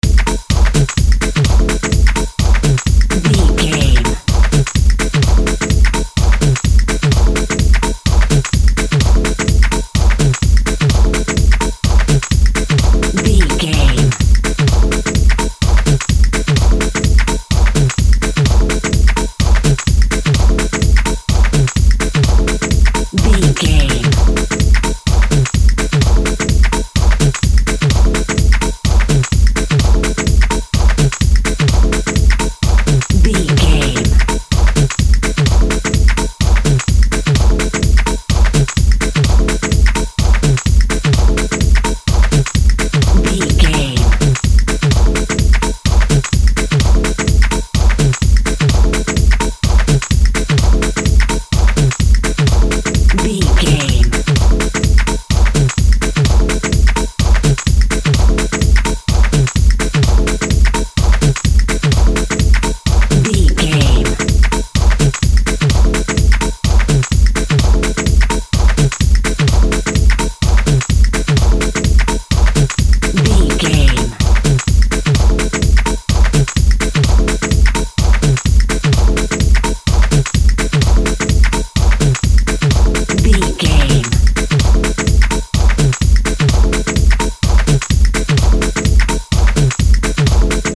Aeolian/Minor
energetic
uplifting
futuristic
hypnotic
drum machine
synthesiser
synth lead
synth bass